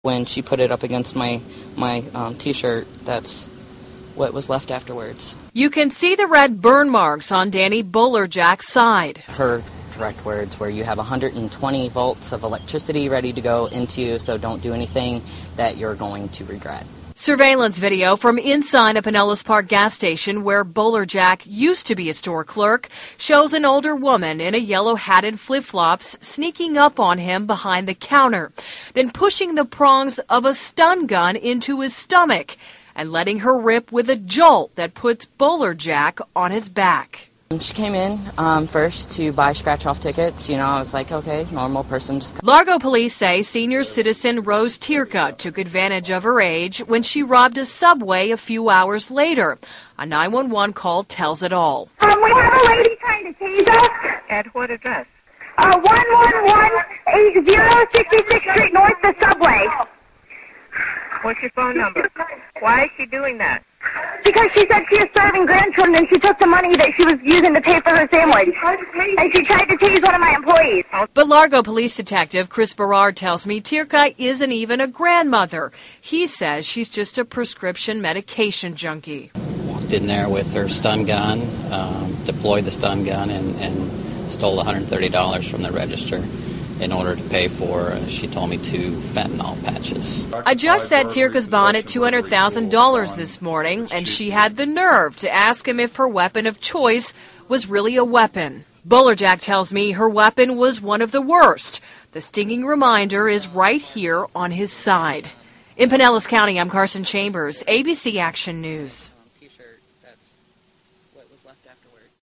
奶奶级劫匪抢超市电击收银员|英语新闻视频